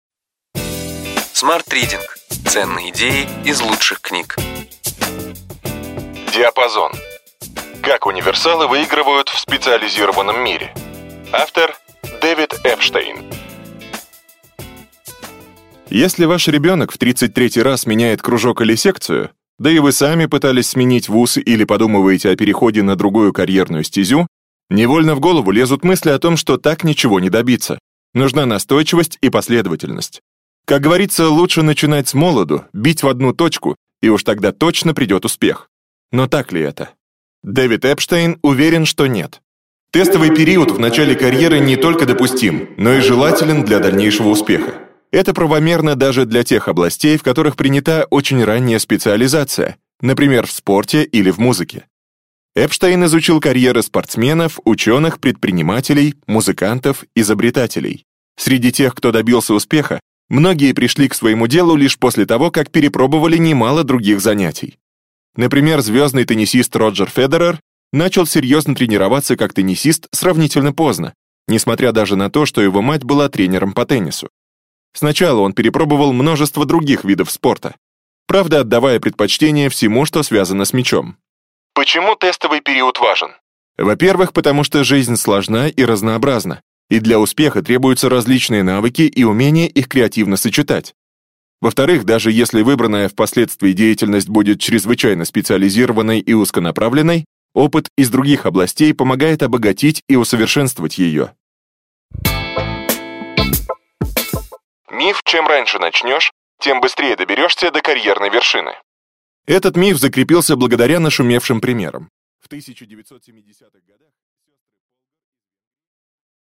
Аудиокнига Ключевые идеи книги: Диапазон. Как универсалы выигрывают в специализированном мире.